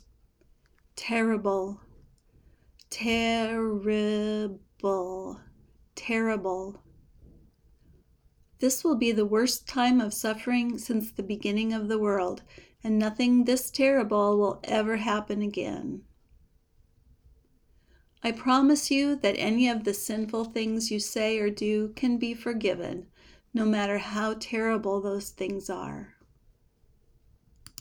ˈtɛr ə bəl (adjective)